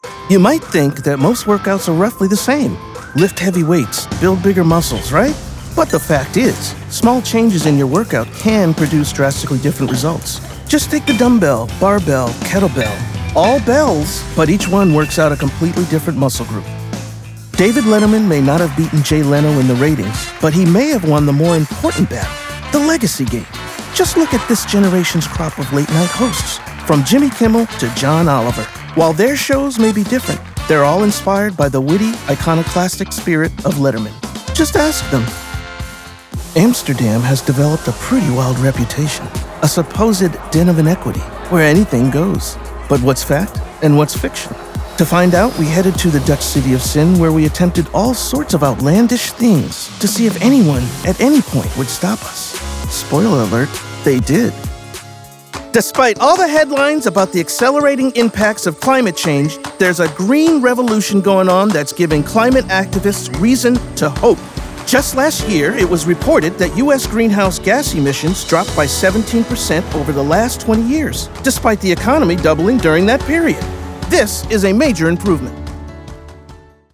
Announcer, conversational, non-announcer, guy next door, energy, high energy, Believable, Familiar, Natural, Upbeat, Real Person, Comforting, Friendly, Smooth, Sports, Persuasive, Classy, Authoritative, Big, Ba...
Heavy base to low base, soothing, energetic, can change it according to the feel required.
0815narrationdemo.mp3